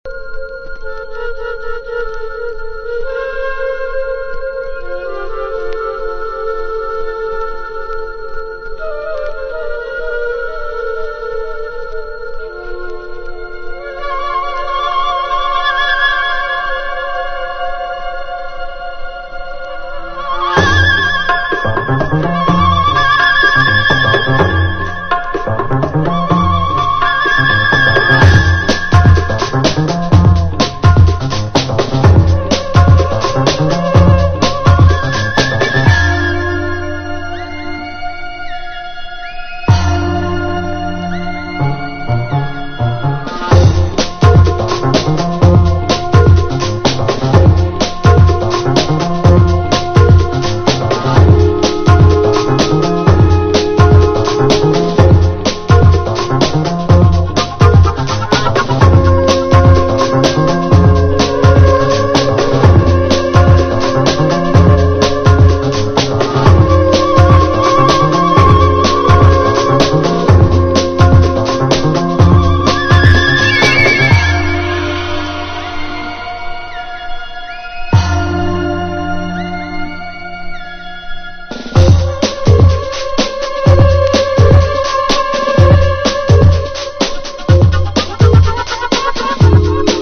BREAK BEATS / BIG BEAT
レーベル色が色濃く浮き出た、エスニックな空気感にブレイクビーツを掛け合わせた心地の良いダンス･ミュージック。